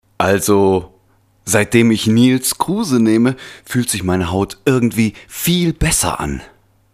Stimmalter: 30-45 Stimmfarbe: weich, seriös, musikalisch, Sonor mit großer Präsenz Genre: Feature, Moderation, Nachrichten, Sachtext, Off-Text Dokumentationen, Telefonansagen, Werbung
Deutscher Sprecher, Musiker.
Sprechprobe: Werbung (Muttersprache):